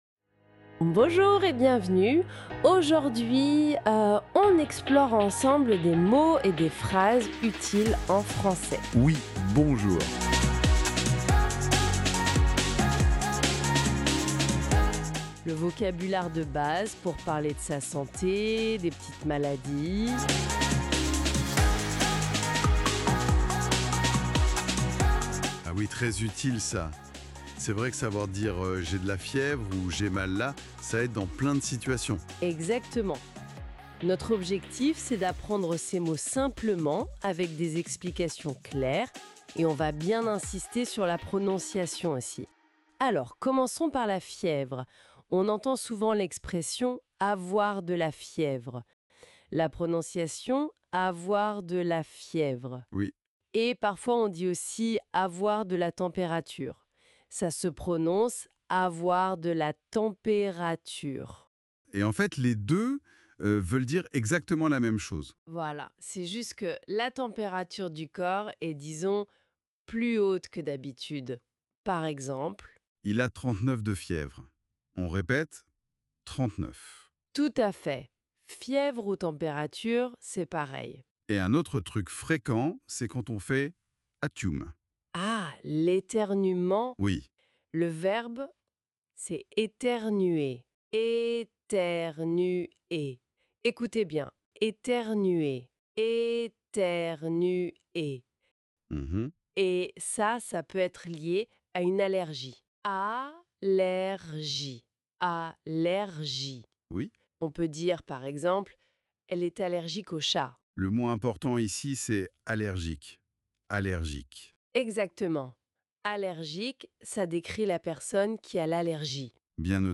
Apprenez le français avec un dialogue pratique + PDF.